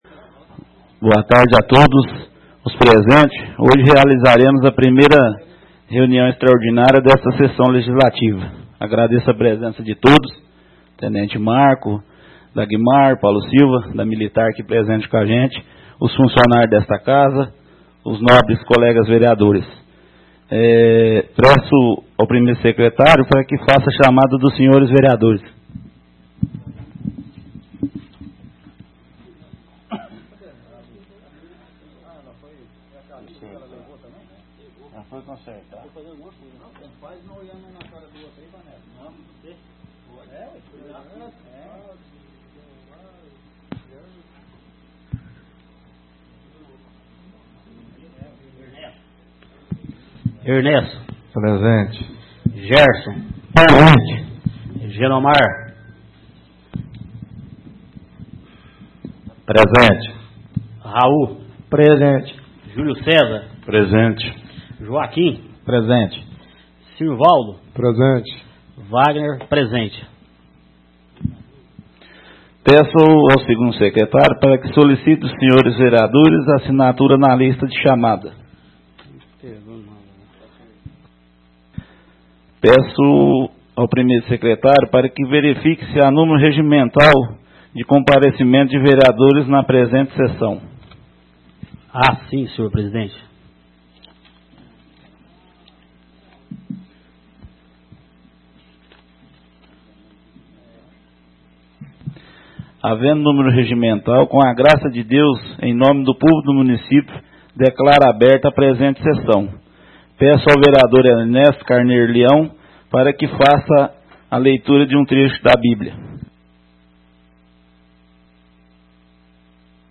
Áudio da 01ª reunião extraordinária de 2018, realizada no dia 22 de Janeiro de 2018, na sala de sessões da Câmara Municipal de Carneirinho, Estado de Minas Gerais.